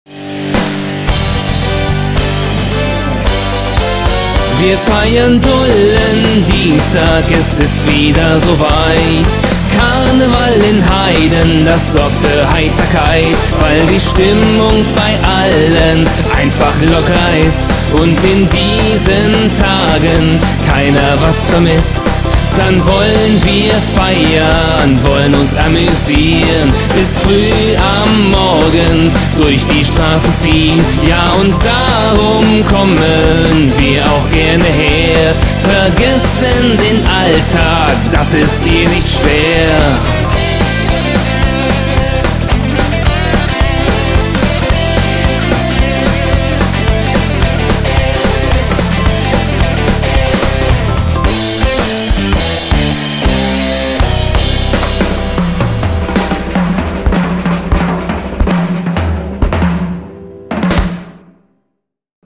04)    Refrain-Special mit Einleitung
(ab dem 2. Refrain bis zum Ende)